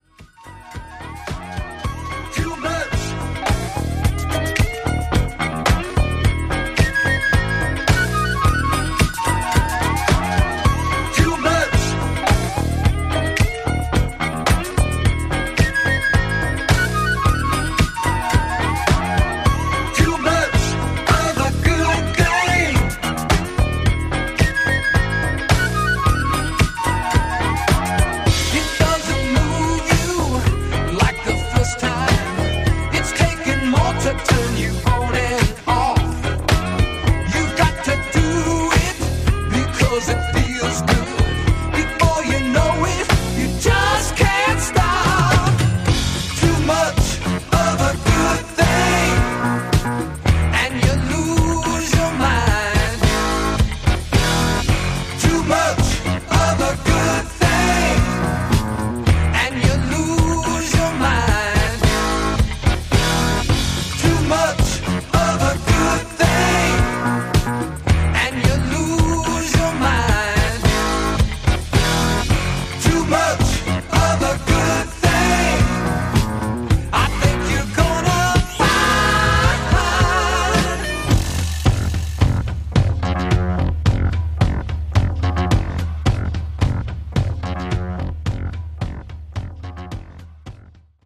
ジャンル(スタイル) NU DISCO / ITALO DISCO / EDITS